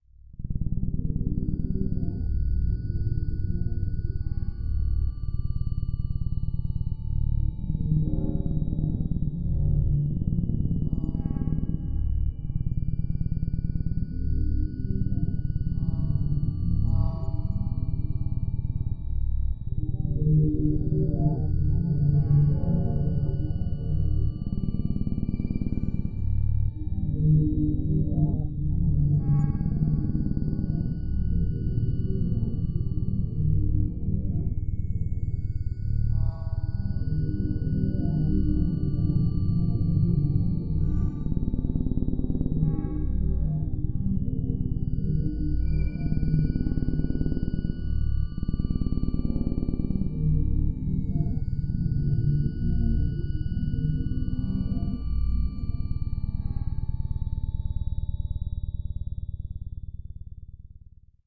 描述：用脉冲星合成产生的样本。 一个具有节奏感的音调性无人驾驶飞机，
Tag: 雄蜂 噪声 脉冲星合成